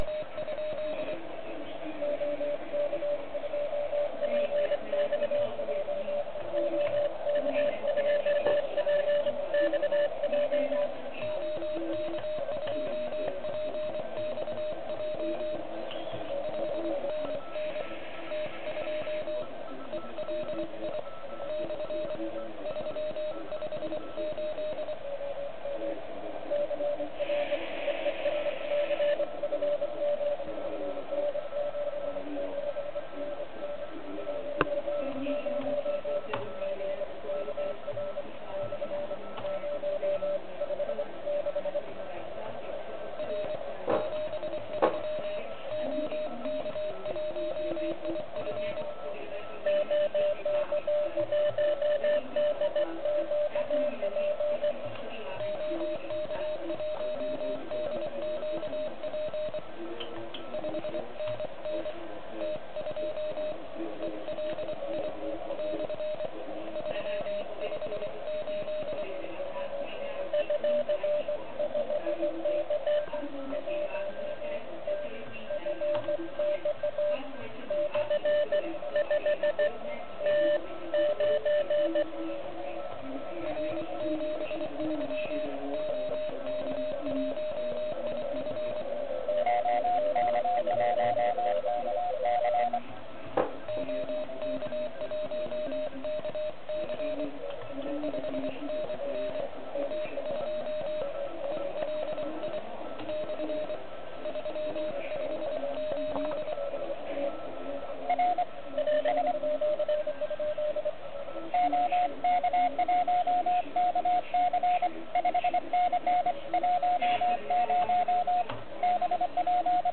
Je zde slyšet plno signálků, ale upřímně řečeno - nic moc.
Mám trvale zapnutý filtr DSP 200Hz.